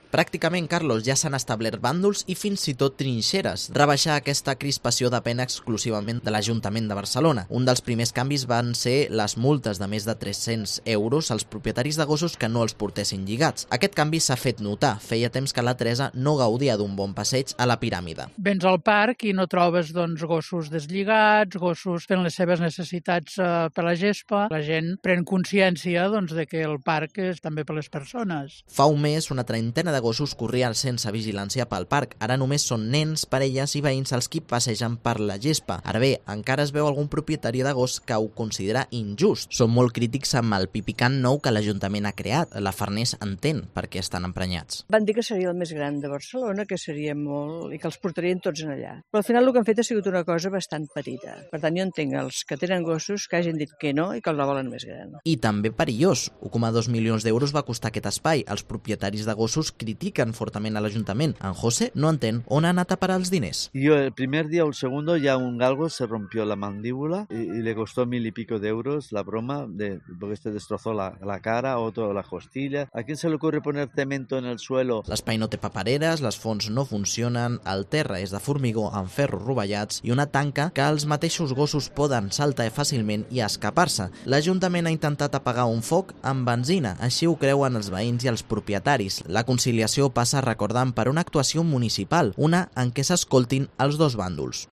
crónica de la guerra entre propietarios de perros y vecinos en el parque de la Estació Nord